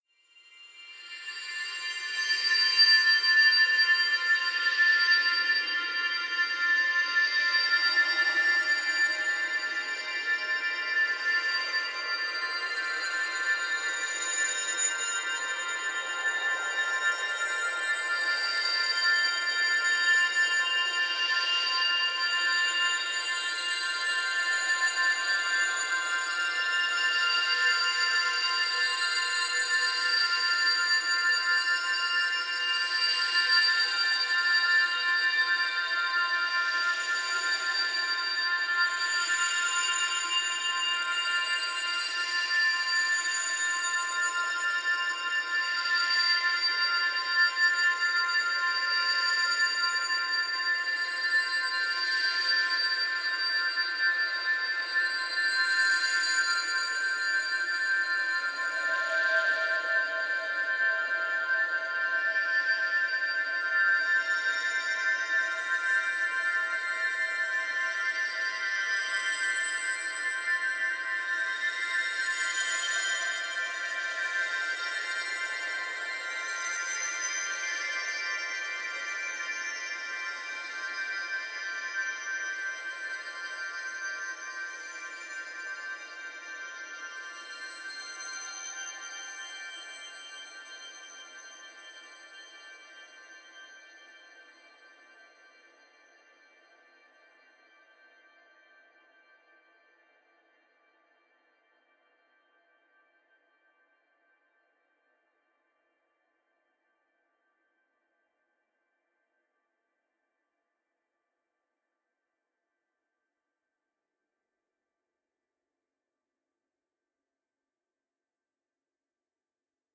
Ambience, Wind Chimes, A
Category 🌿 Nature
ambiance ambience background Background bright Bright calm Calm sound effect free sound royalty free Nature